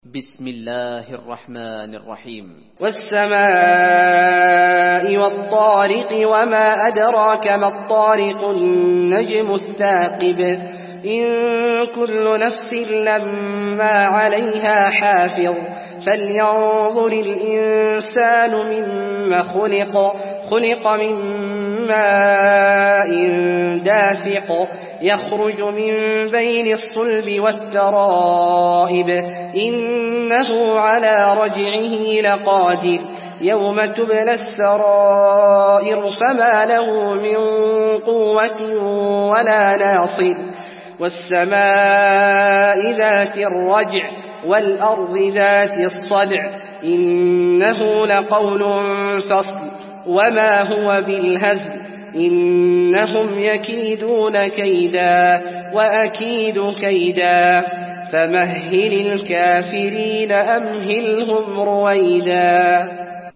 المكان: المسجد النبوي الطارق